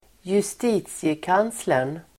Uttal: [²jus:t'i:tsiekan:slern]